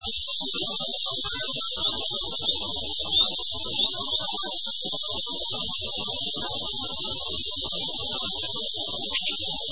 [セミ]
街中でもアブラゼミやミンミンゼミが声の大きさを競い合います。